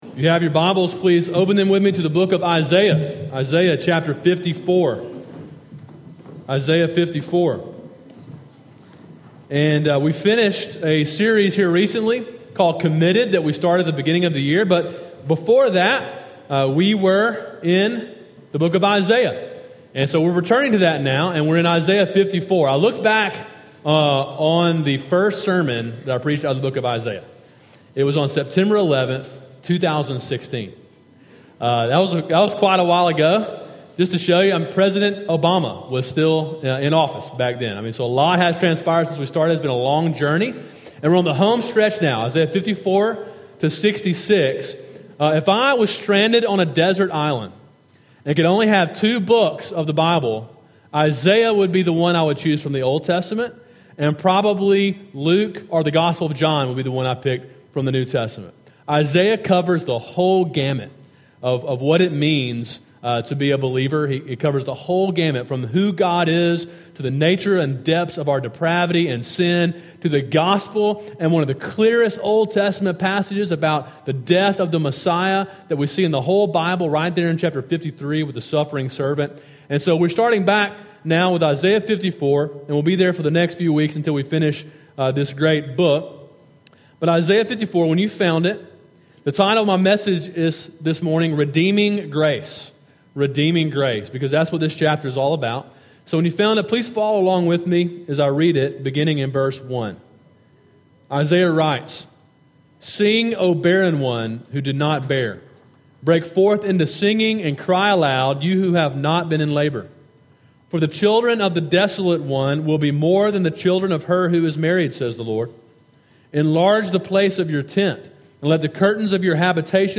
Sermon: “Redeeming Grace” (Isaiah 54) – Calvary Baptist Church
sermon2-18-18.mp3